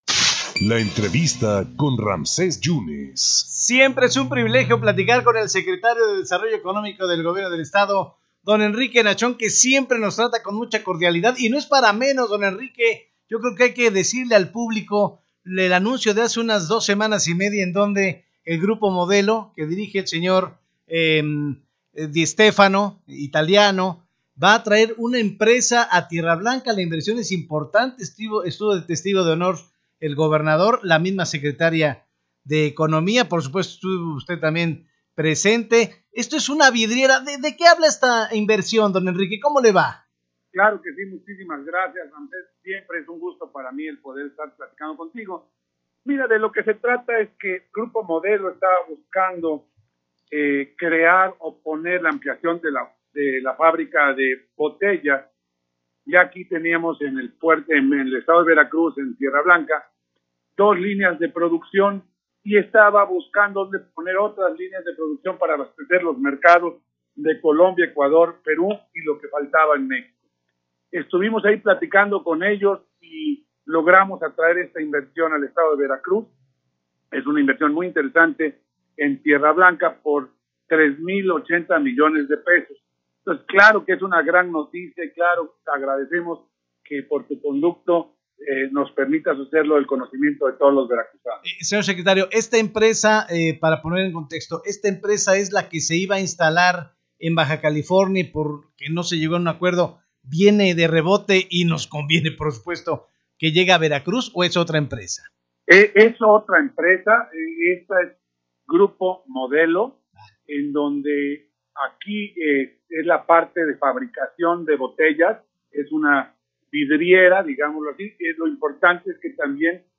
Redacción/Xalapa. El secretario de Desarrollo Económico y Portuario (Sedecop) en Veracruz, Enrique de Jesús Nachón García, comenta en la entrevista sobre la ampliación que hará Grupo Modelo, para la instalación de una fábrica de botellas en Tierra Blanca.